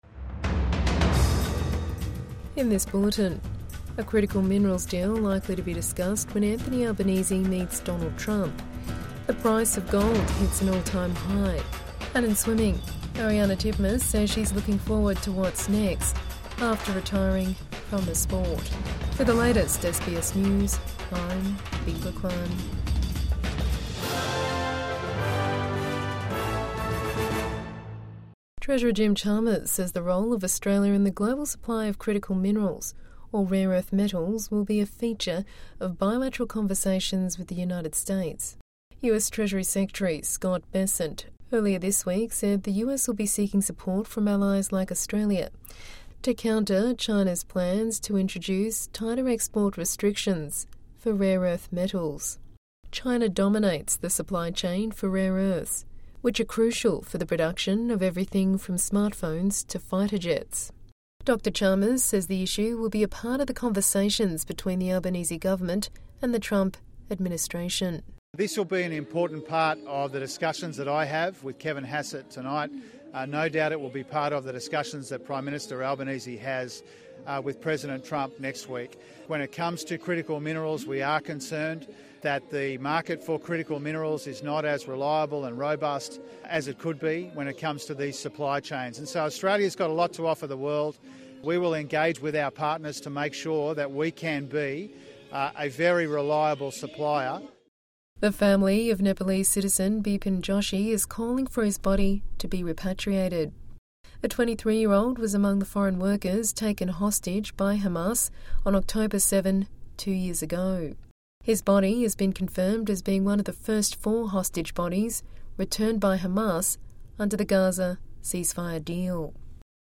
Evening News Bulletin